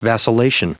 Prononciation du mot vacillation en anglais (fichier audio)
Prononciation du mot : vacillation